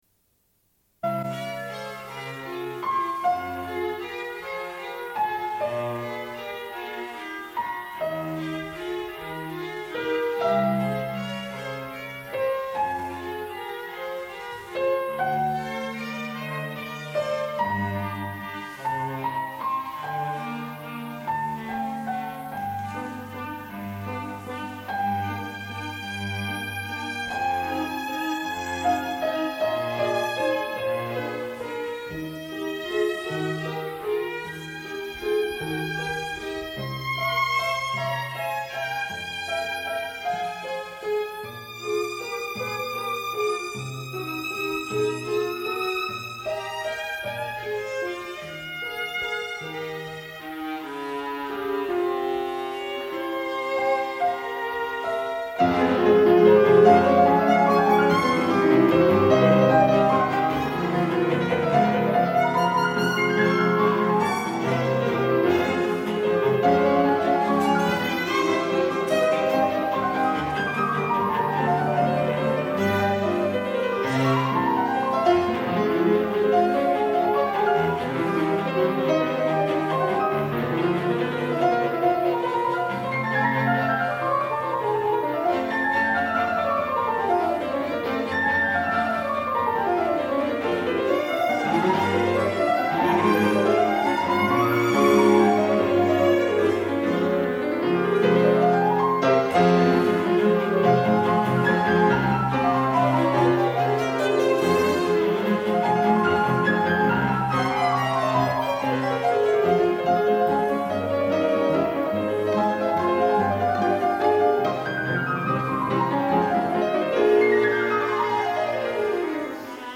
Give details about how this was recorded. Une cassette audio, face B28:36